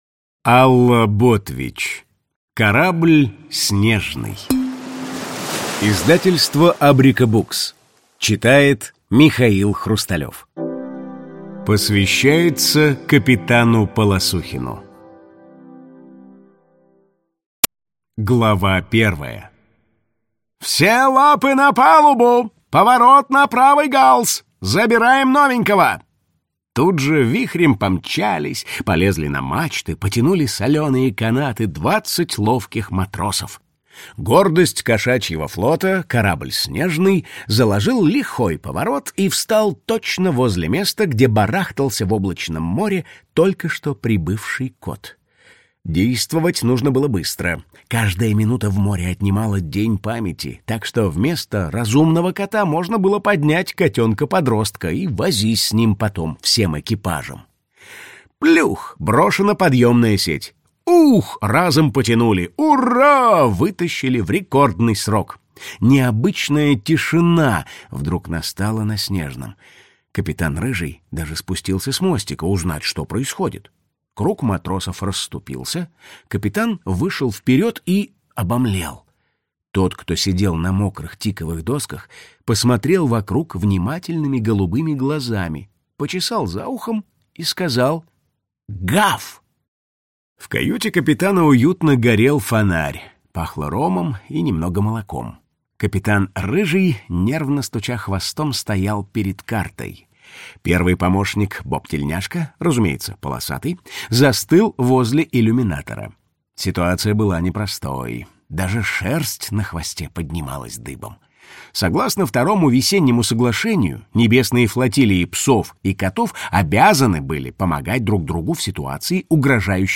Аудиокнига Корабль «Снежный» | Библиотека аудиокниг
Прослушать и бесплатно скачать фрагмент аудиокниги